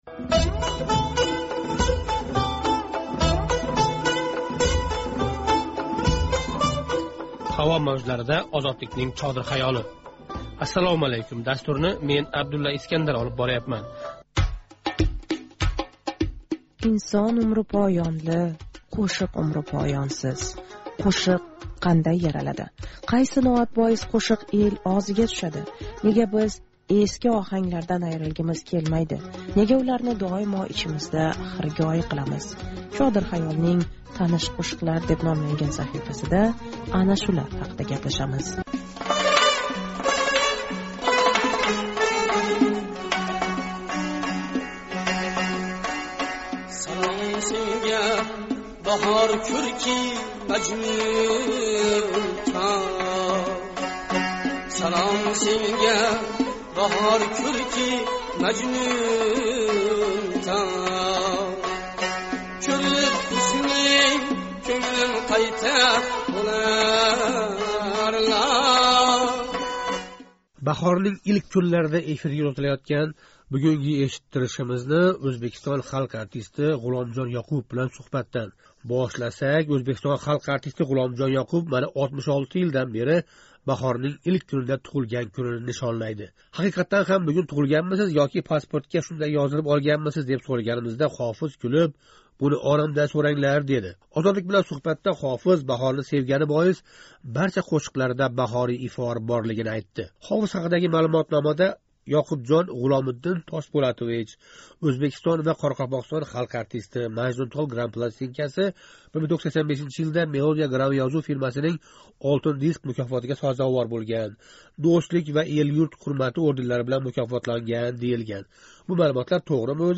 Ғуломжон Ëқубов билан суҳбат